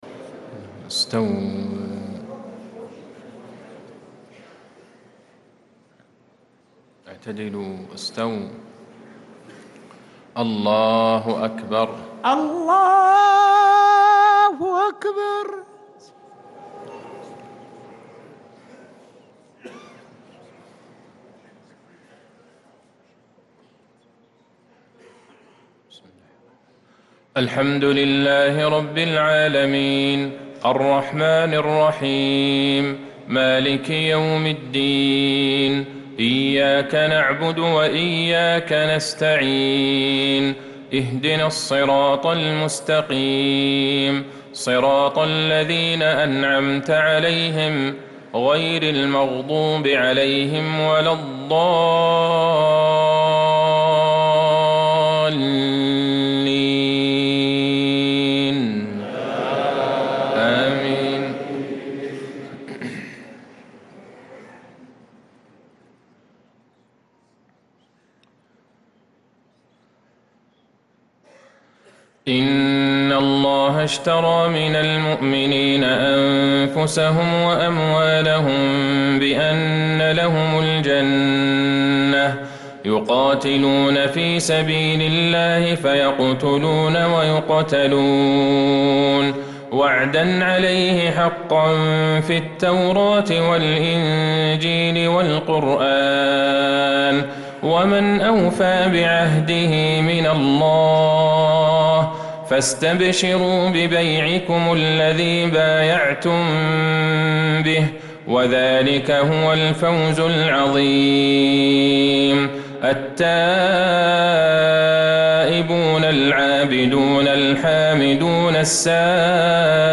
تِلَاوَات الْحَرَمَيْن